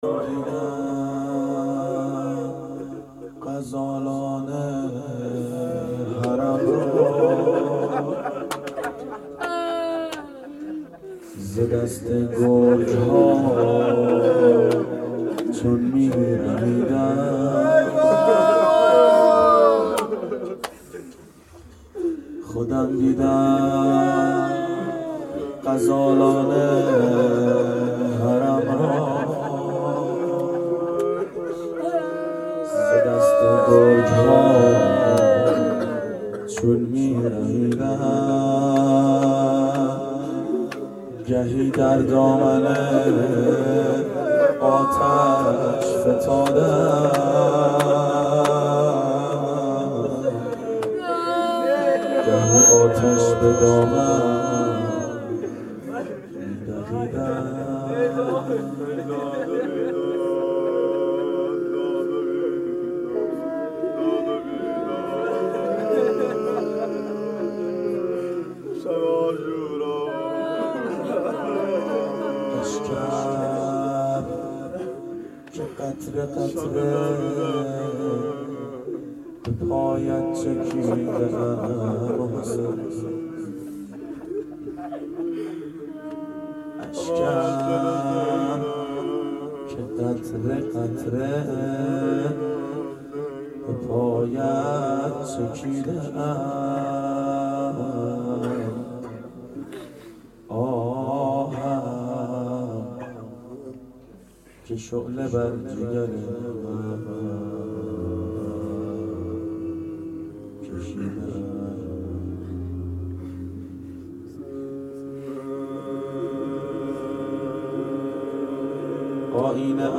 روضه شب عاشورا
شب عاشورا 92 هیأت عاشقان اباالفضل علیه السلام منارجنبان
01-روضه-شب-عاشورا.mp3